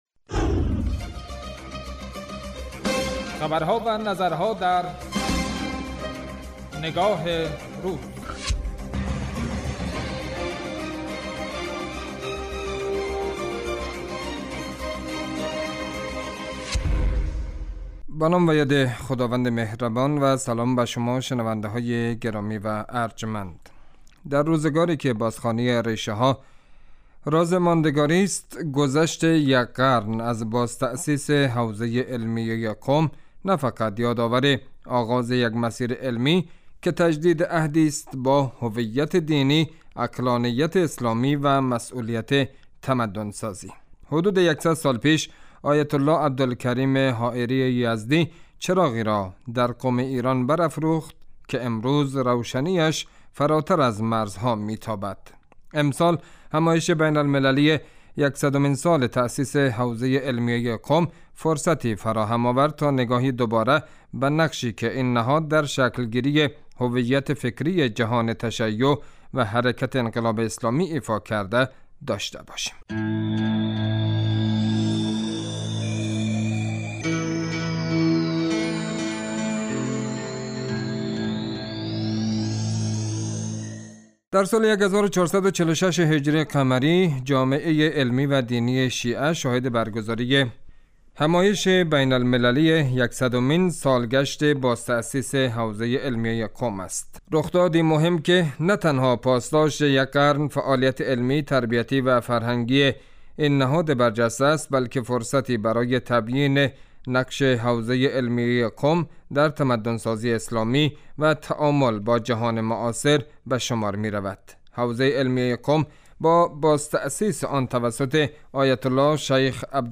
رادیو